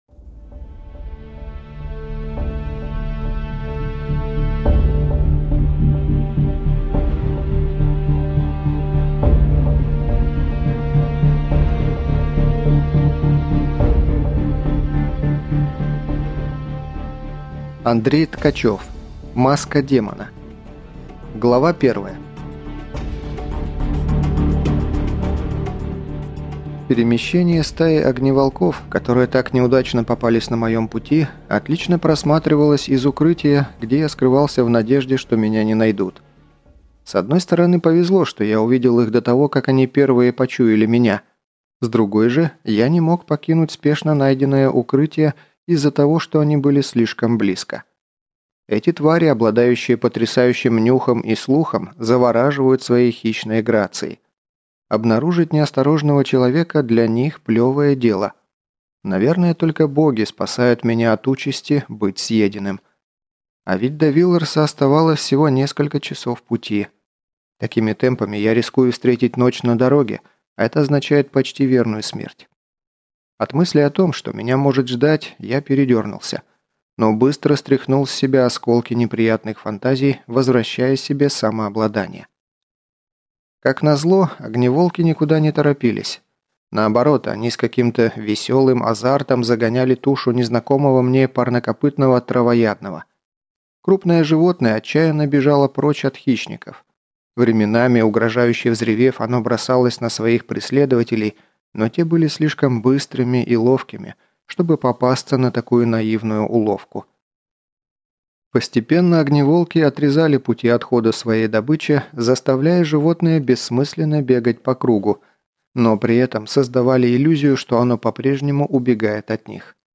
Прослушать фрагмент аудиокниги Маска демона Андрей Ткачев Произведений: 3 Скачать бесплатно книгу Скачать в MP3 Вы скачиваете фрагмент книги, предоставленный издательством